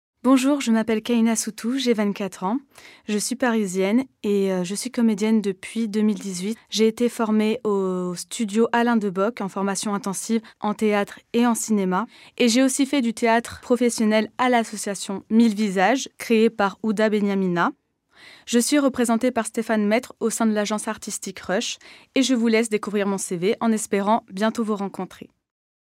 Présentation
Tessiture voix : médium léger
Voix : Bambin garçon, Enfant garçon, Adolescent fille, jeune femme.
Voix off